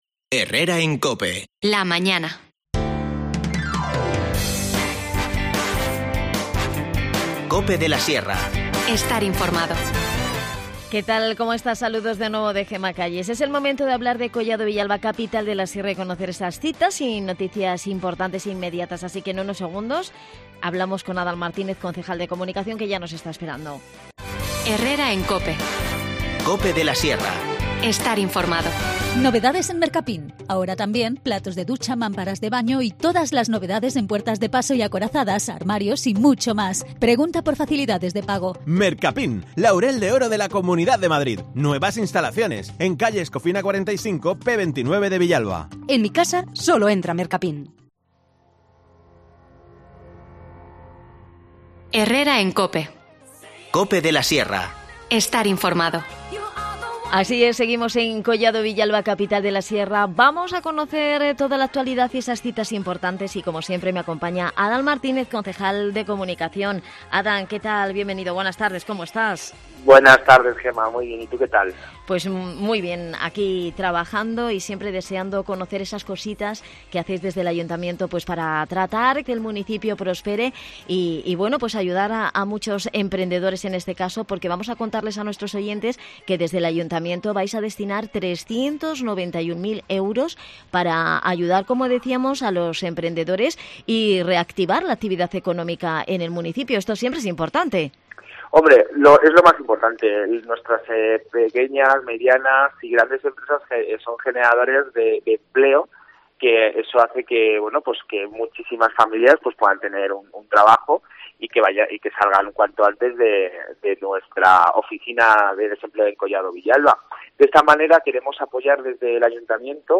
Hemos hablado con Adan Martínez, concejal de Comunicación, de éstas y otras iniciativas de Collado Villalba en el programa.